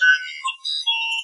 computer2.wav